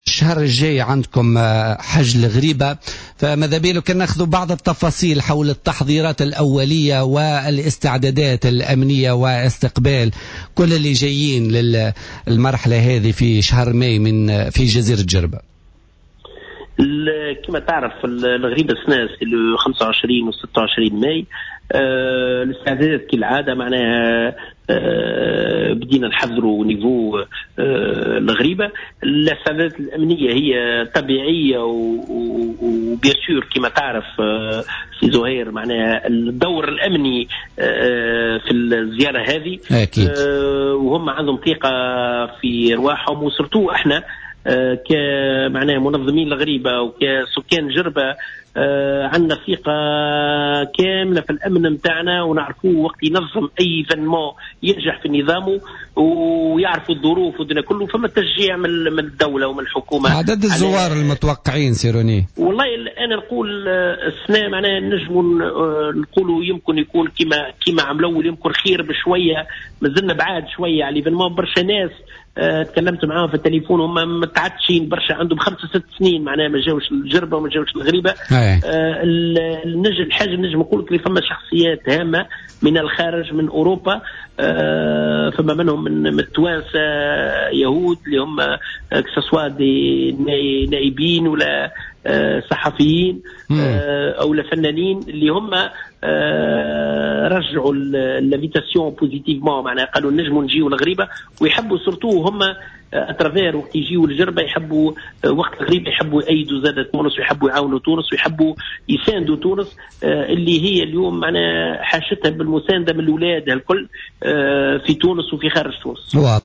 Trabelsi a assuré, au micro de Jawhara Fm, que les pèlerins ont une totale confiance aux forces de sécurité pour assurer la réussite du pèlerinage.